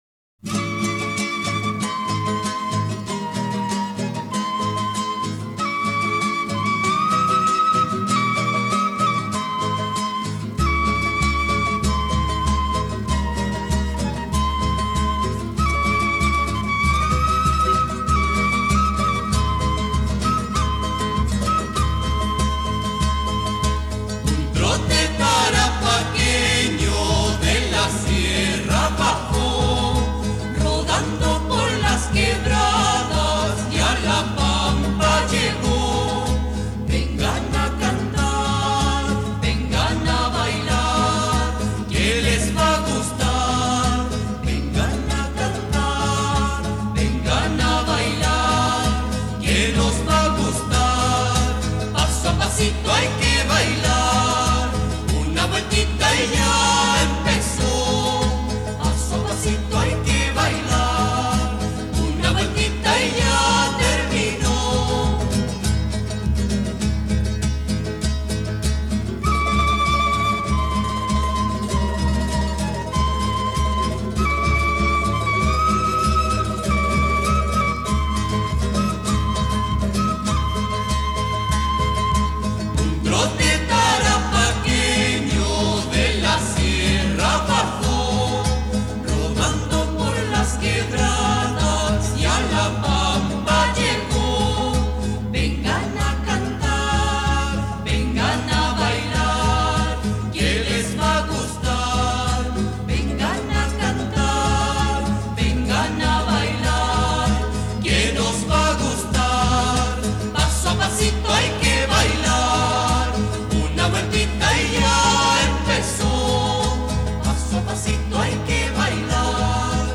Trote tarapaqueño